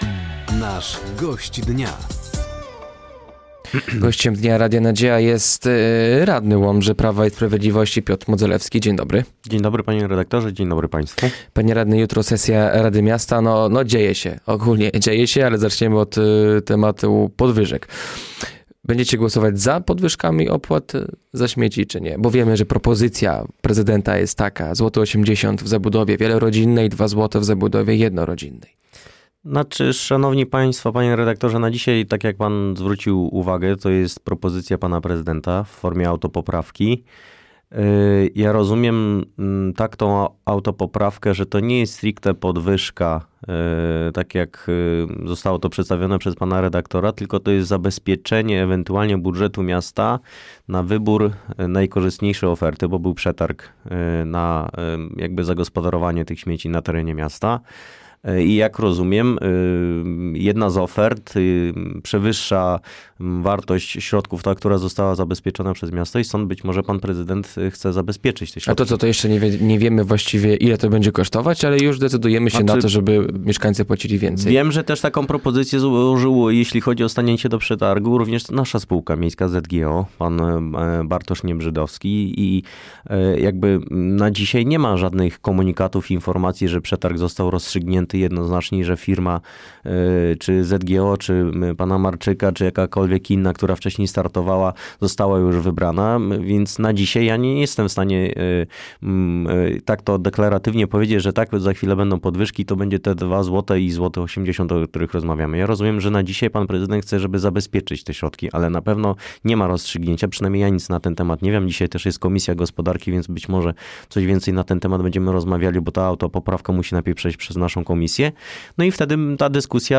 Gościem Dnia Radia Nadzieja był Piotr Modzelewski, radny Miasta Łomża.